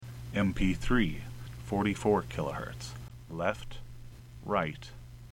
120 min mp3 file containing 440Hz sine